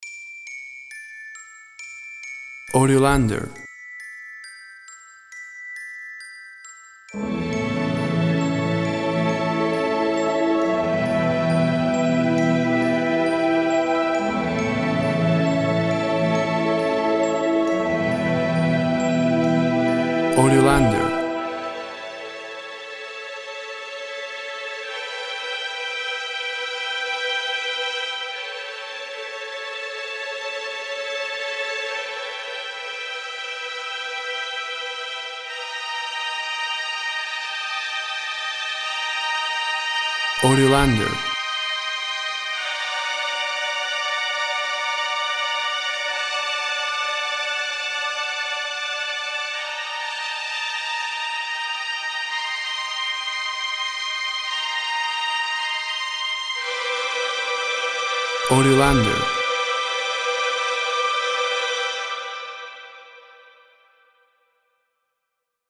Bells introduce orchestra full of anticipation.
Tempo (BPM) 66